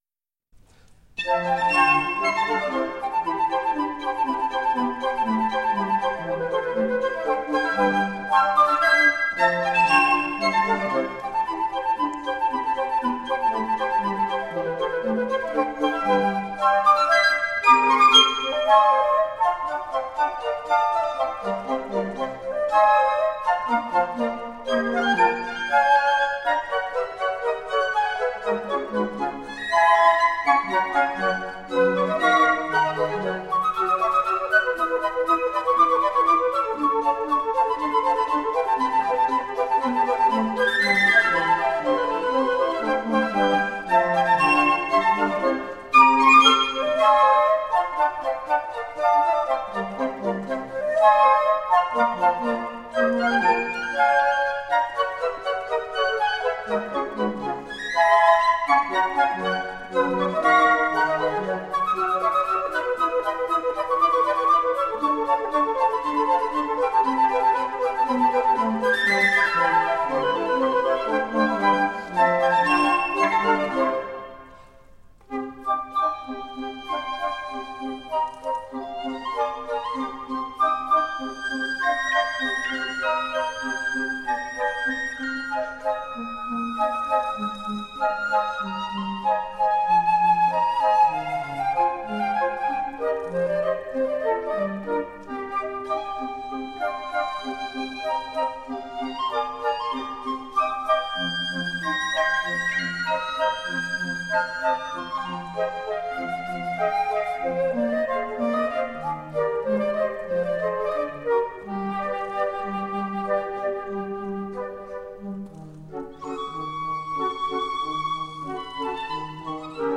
Flautists with finesse, intelligence and grooves.
flute ensemble
recorded live in Hakodate City Art Hall on 22 March 1999
Classical, Baroque, Impressionism, Instrumental
Flute